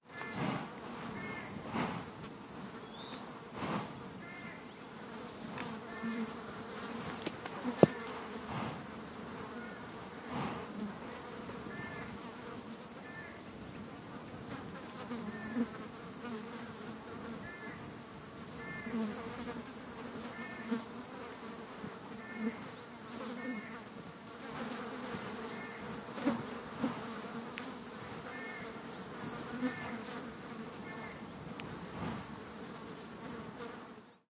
苍蝇嗡嗡叫
描述：苍蝇嗡嗡叫，许多苍蝇飞向不同的方向
标签： 蜂鸣器 昆虫 苍蝇 厕所
声道立体声